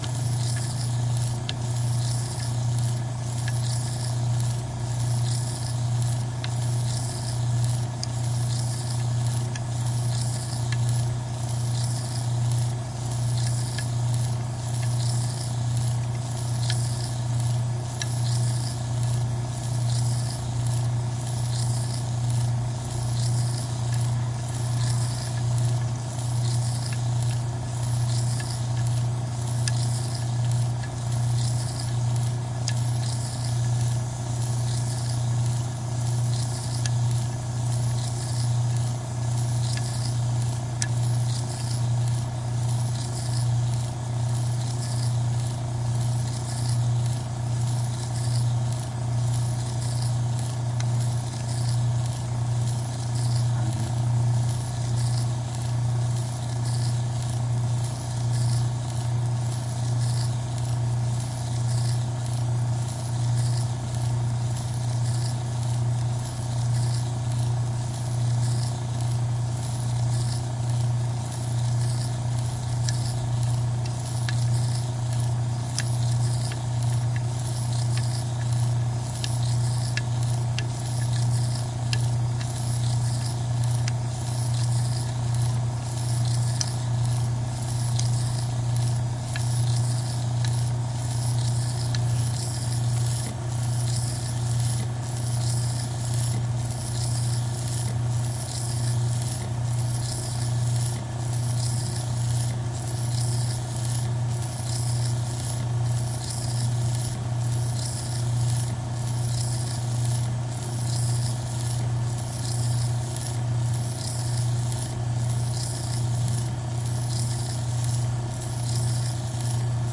盒式磁带 " 卷对卷磁带机，两卷都在中间转动麦克风
描述：卷轴到卷轴磁带机两个卷轴在mid.flac转动麦克风
Tag: 卷轴 卷轴 磁带 机器 车削